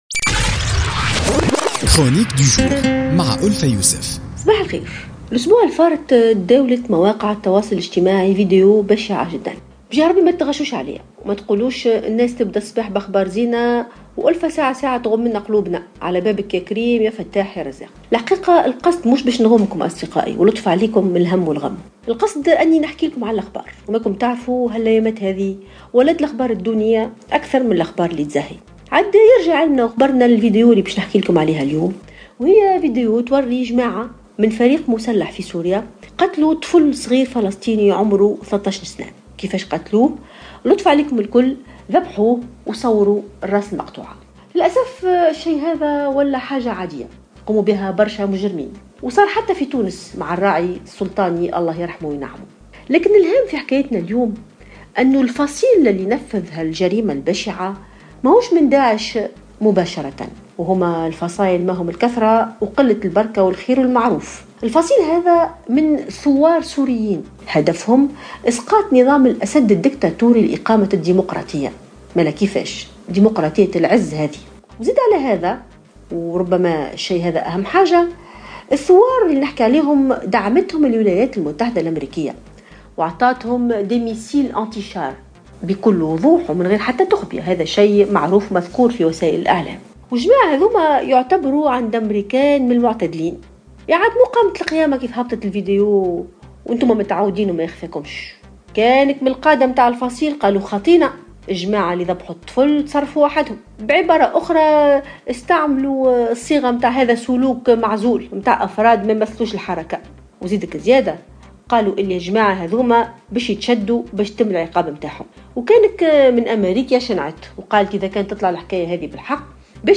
تطرقت الكاتب ألفة يوسف في افتتاحيتها اليوم الاثنين إلى الفيديو المروع الذي يظهر فيه فصيل معارض في سوريا وهو بصدد ذبح طفل فلسطيني لاجئ في سوريا .